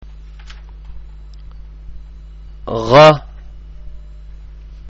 ഉച്ചാരണം (പ്ലേ ബട്ടണ്‍ അമര്‍ത്തുക)
019_Ghayn[1].mp3